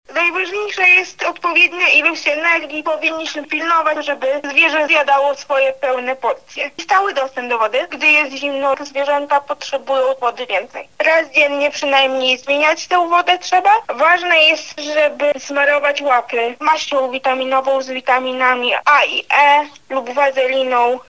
mówi behawiorystka